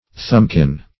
Search Result for " thumbkin" : The Collaborative International Dictionary of English v.0.48: Thumbkin \Thumb"kin\, n. An instrument of torture for compressing the thumb; a thumbscrew.